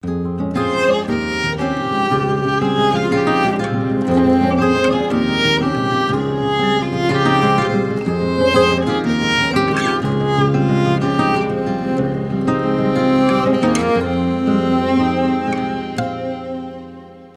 без слов
скрипка
спокойные , инструментальные